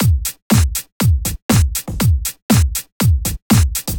34 Drumloop.wav